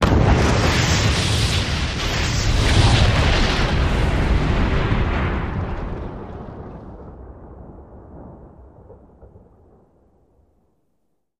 Explosions; Multiple 01